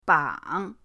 bang3.mp3